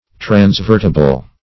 Search Result for " transvertible" : The Collaborative International Dictionary of English v.0.48: Transvertible \Trans*vert"i*ble\, a. Capable of being transverted.